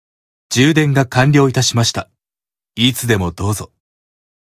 Barbatos_AP_Notification_(NB)_Voice.ogg.mp3